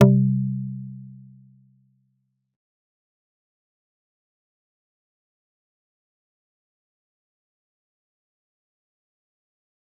G_Kalimba-C3-mf.wav